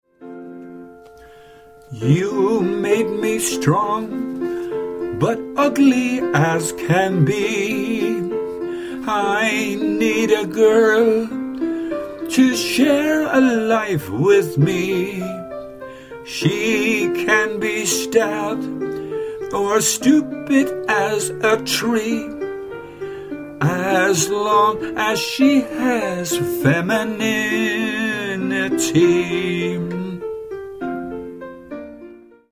Sample from the Vocal MP3